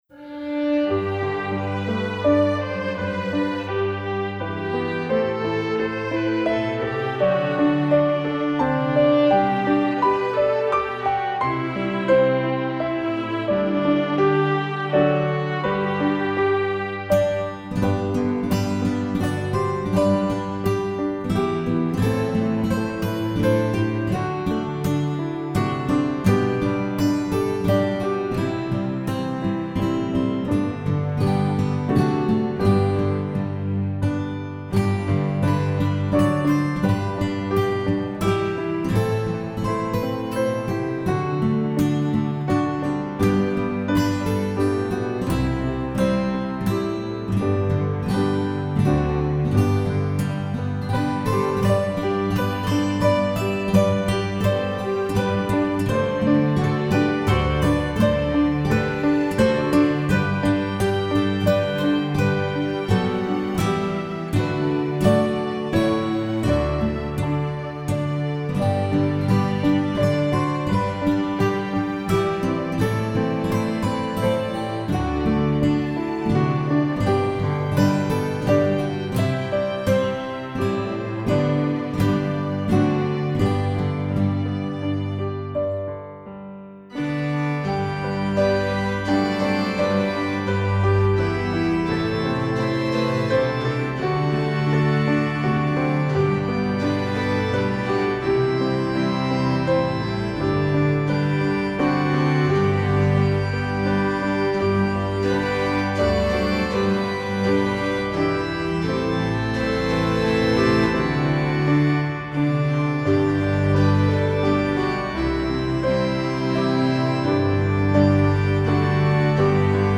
• instrumental 00:00